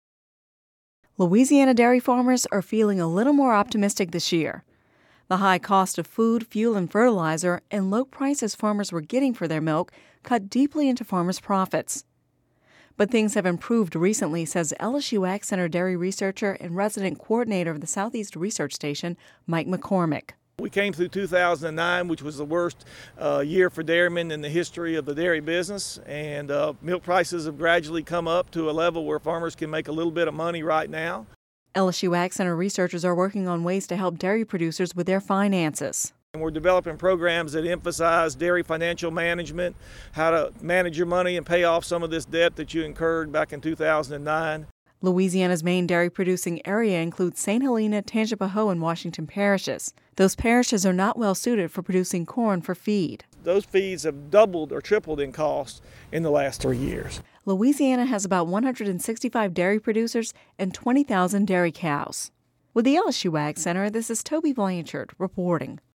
(Radio News 12/27/10) Louisiana dairy farmers are feeling a little more optimistic this year.